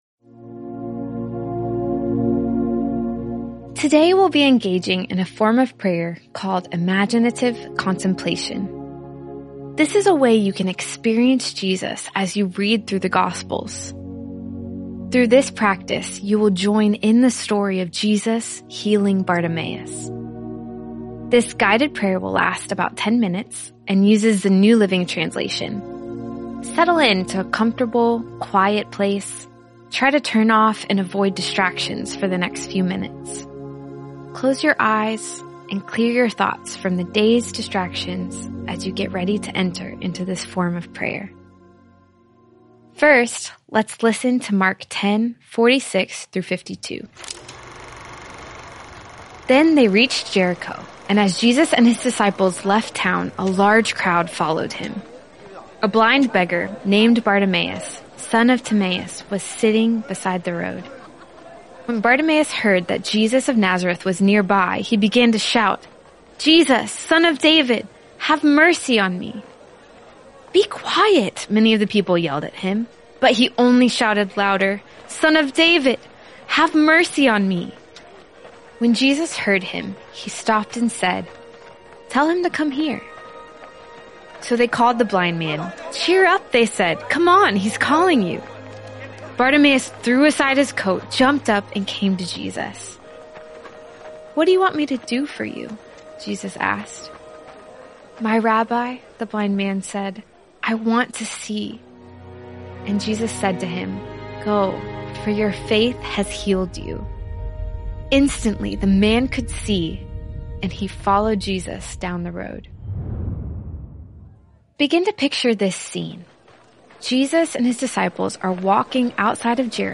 This guided prayer will last about 10 minutes and uses the New Living Translation.